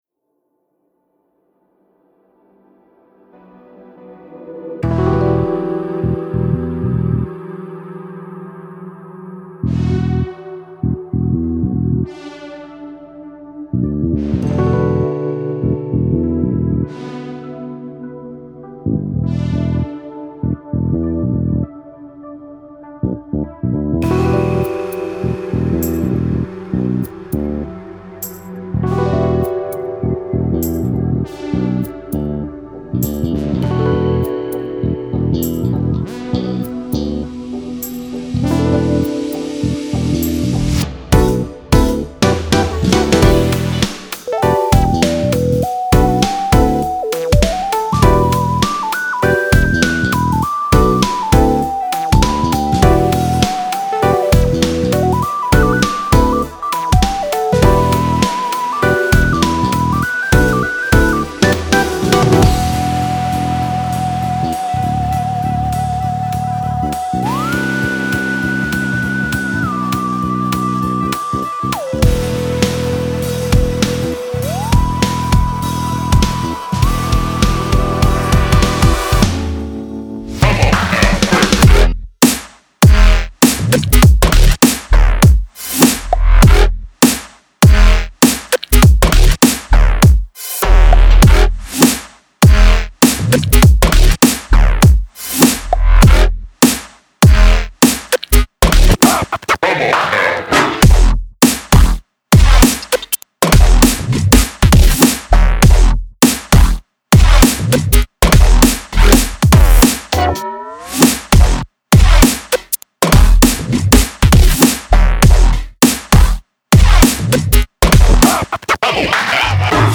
is a glitch hop song